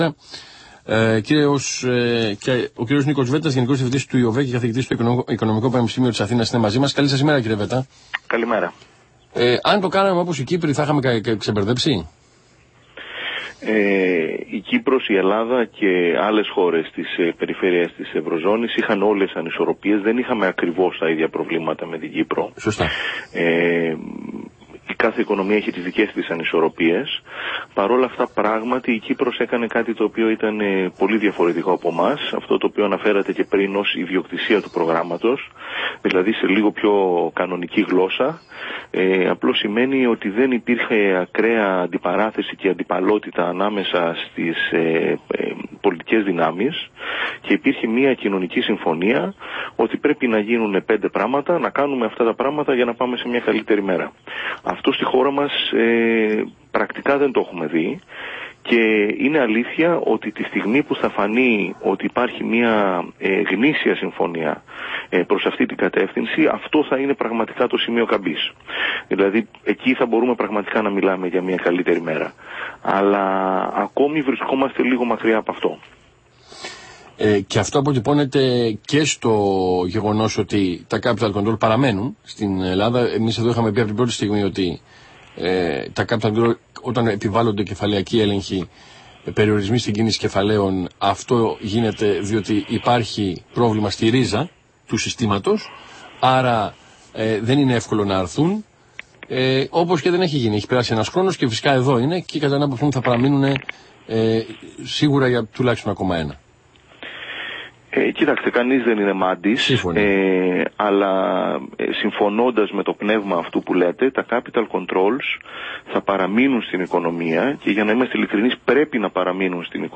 Συνέντευξη
στη ραδιοφωνική εκπομπή Η Τρόικα του Αθήνα 9,84, με αναφορά στην οικονομική συγκυρία και τις προοπτικές.